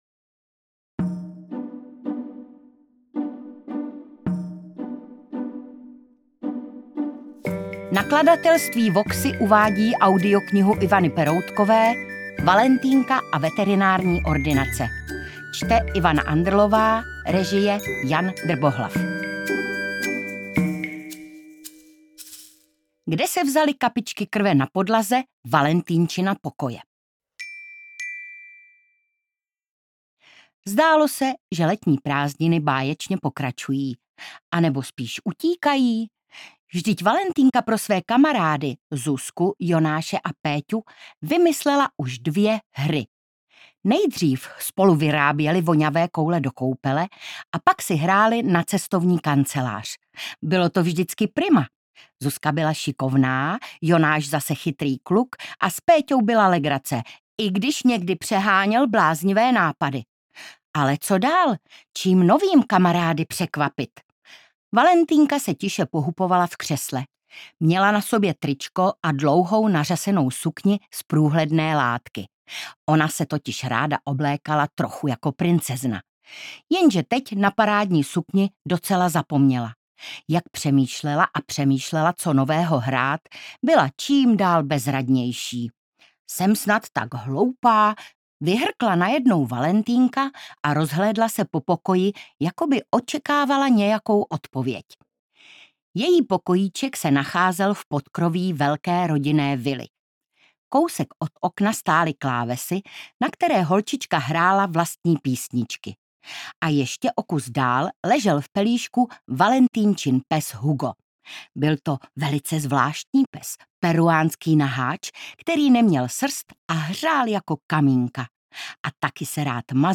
Interpret:  Ivana Andrlová